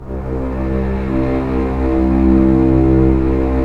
Index of /90_sSampleCDs/Roland LCDP13 String Sections/STR_Orchestral p/STR_Orch. p Slow